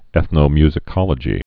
(ĕthnō-myzĭ-kŏlə-jē)